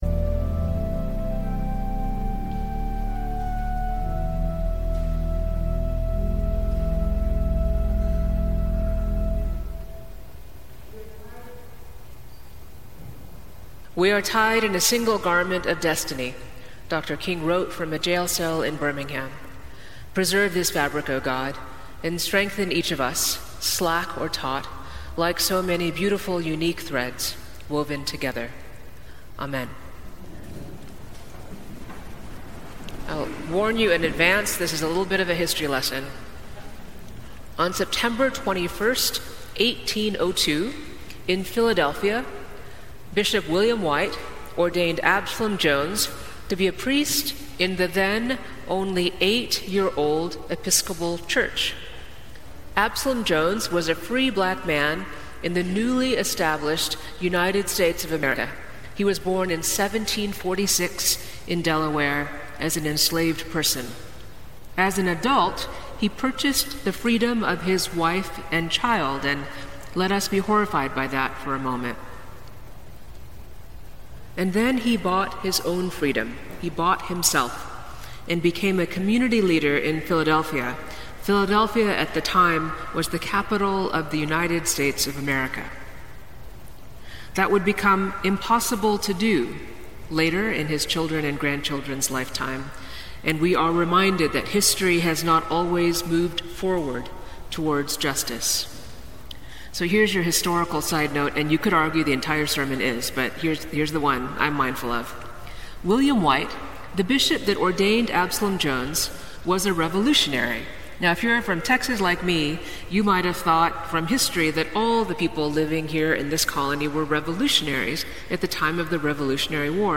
Sermons from St. Luke's Episcopal Church in Atlanta